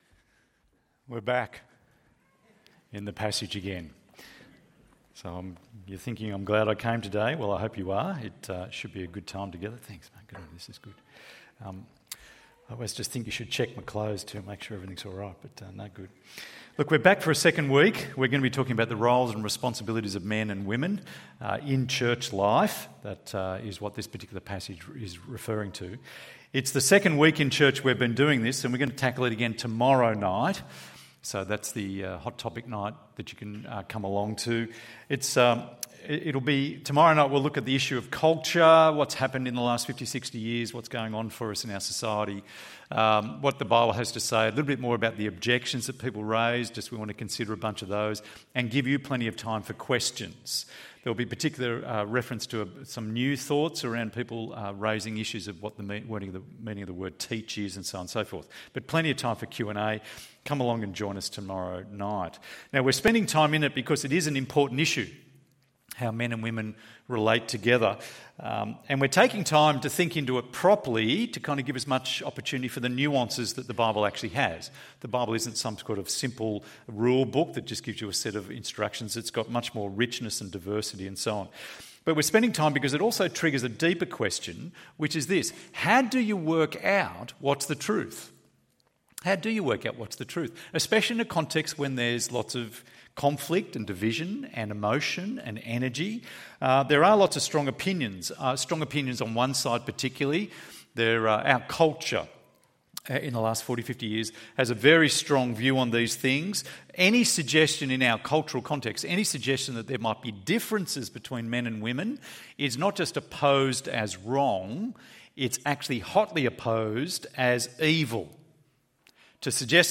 The Glorious Diversity of Men and Women ~ EV Church Sermons Podcast